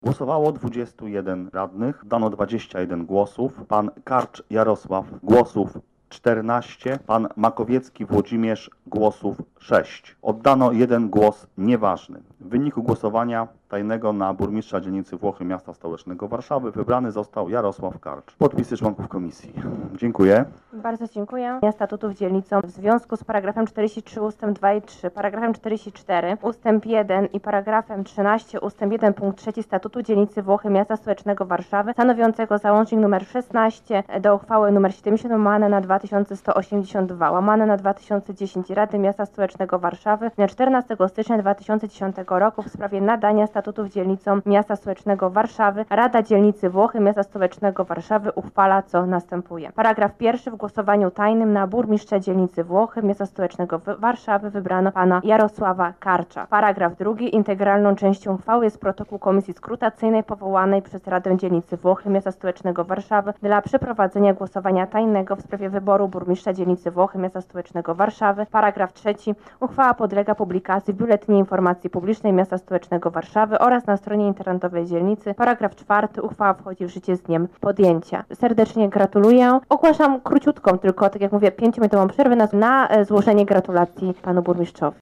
Poniżej ogłoszenie wyników głosowania: